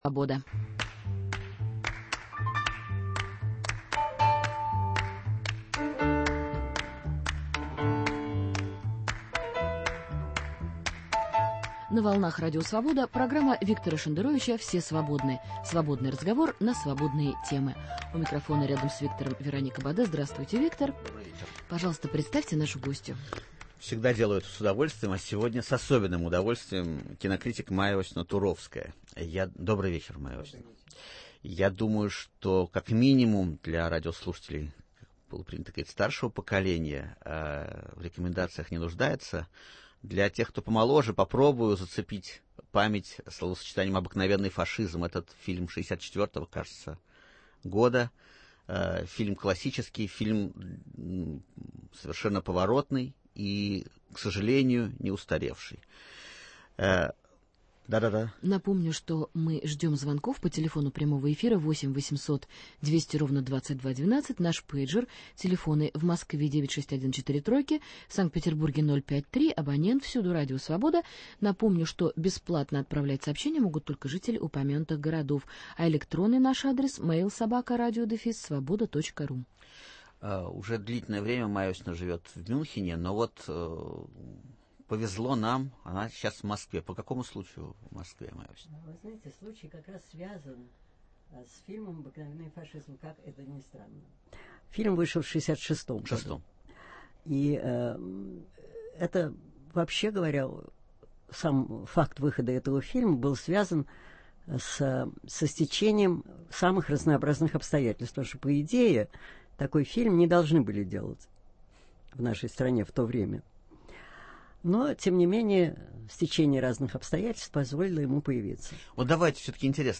В гостях у Виктора Шендеровича – театровед, критик и сценарист Майя Туровская.